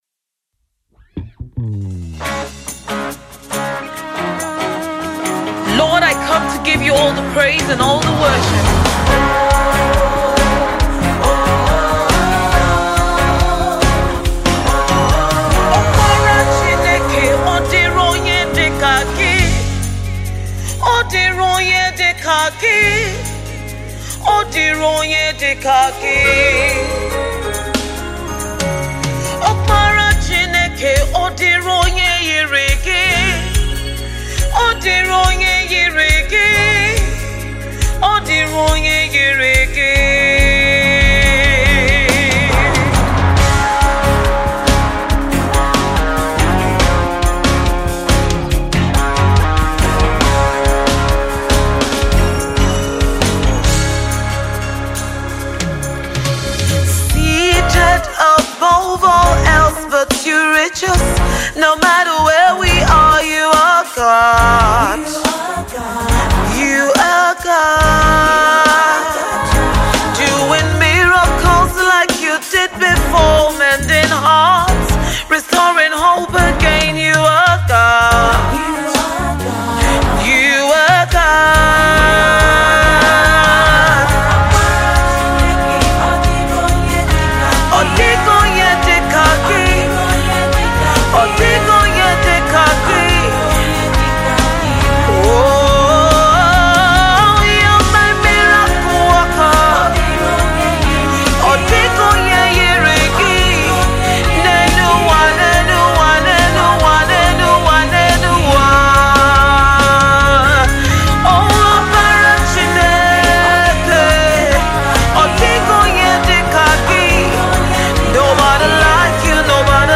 a beautiful song of worship and praise to God Almighty.
Gospel